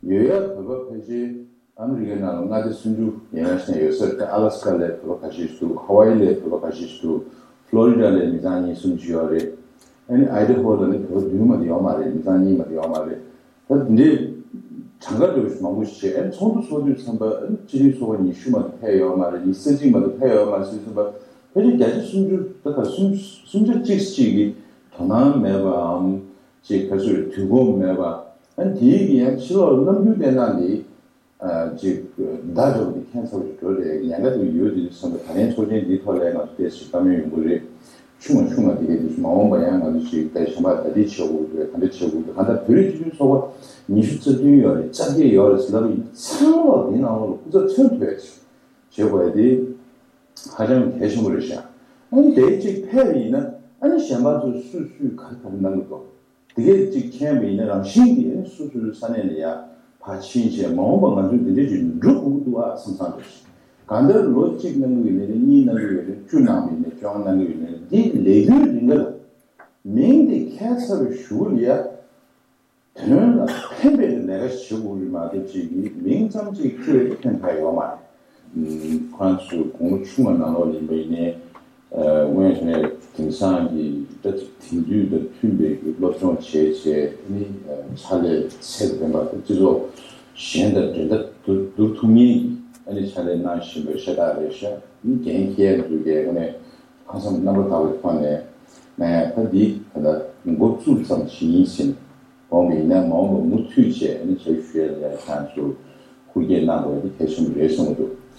བྱང་ཨ་རི་སྐུ་ཚབ་དོན་གཅོད་སྐུ་ངོ་སྤེན་པ་ཚེ་རིང་ལགས་ནས་ཕྱི་ཚེས་༨ཉིན་བོད་རིགས་སྤྱི་མཐུན་ཚོགས་པའི་ཚོགས་མི་རྣམས་ལ་གསུང་བཤད་གནང་བ།